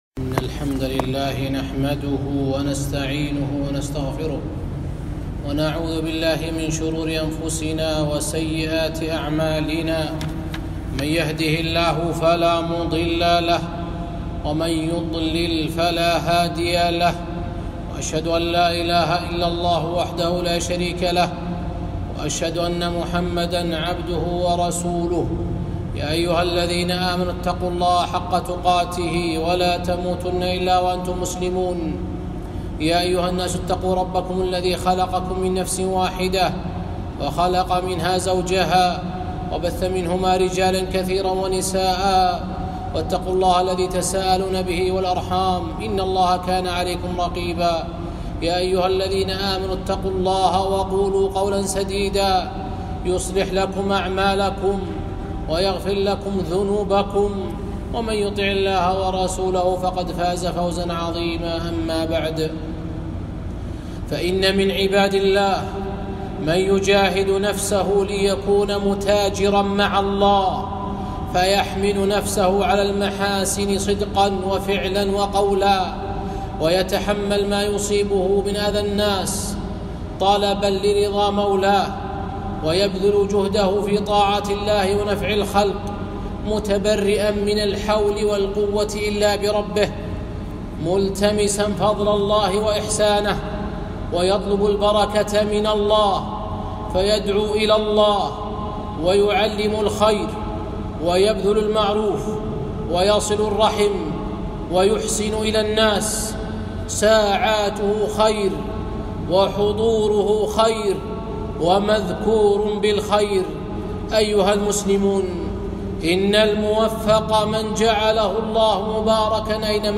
خطبة - وَجَعَلَنِي مُبَارَكًا أَيْنَ مَا كُنتُ